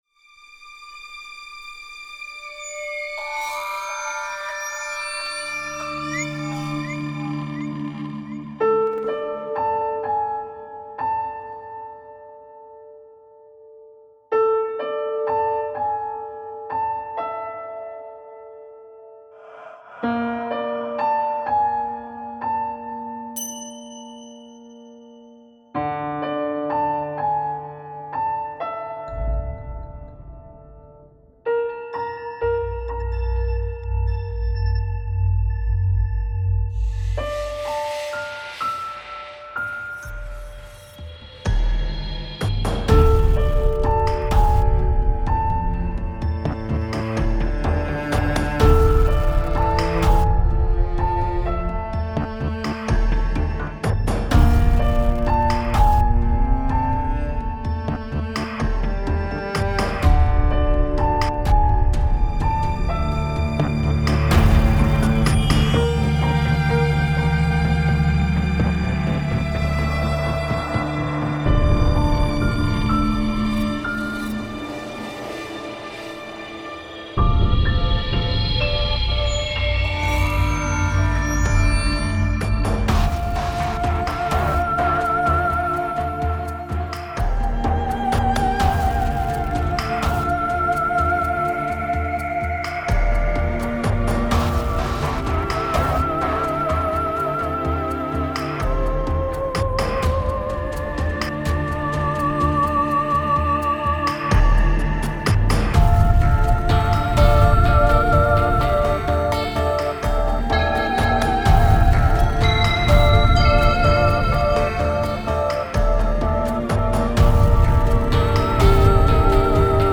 Snippet 3 – Musik für Artisten